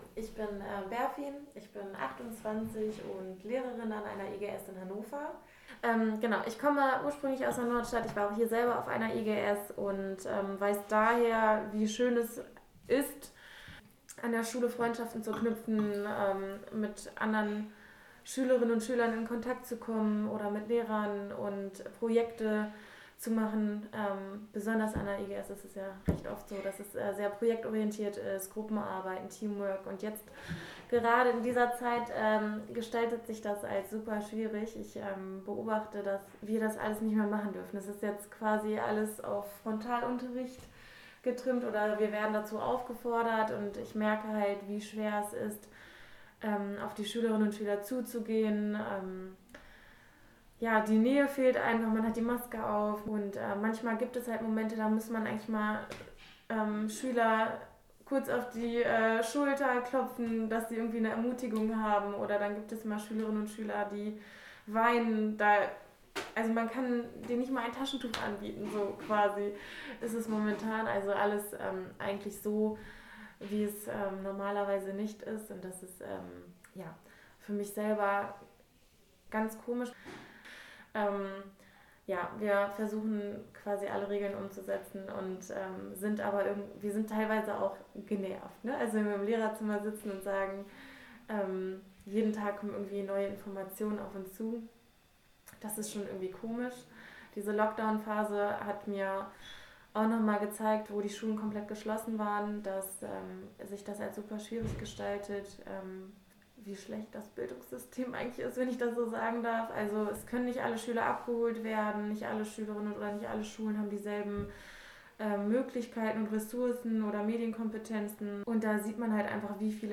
Eine Lehrerin berichtet, wie eine Krankheit trennt, eine Klasse aber zusammenhält.